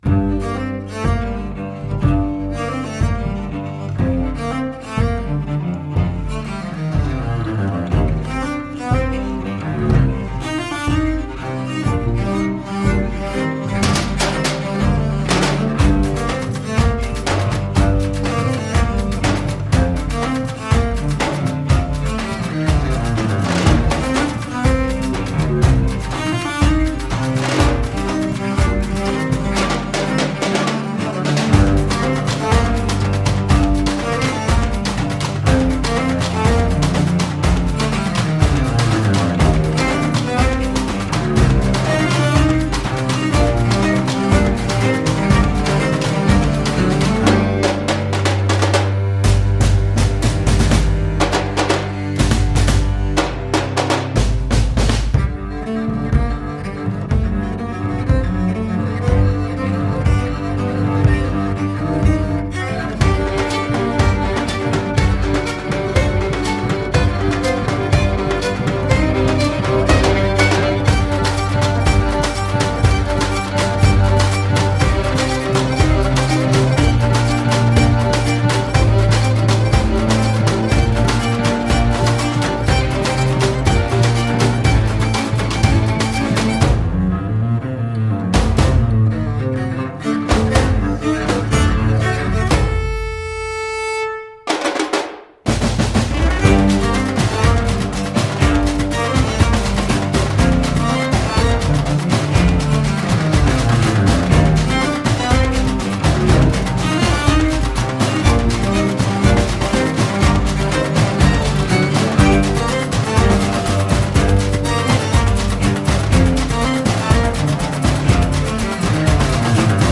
Ici le ton est dramatique, voir désespéré.